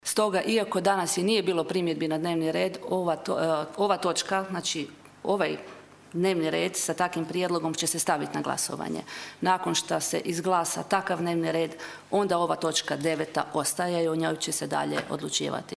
Današnja sjednica Gradskog vijeća Labina započela je više nego zanimljivo.
Na njezine navode odgovorila je predsjednica Gradskog vijeća Nena Ružić: (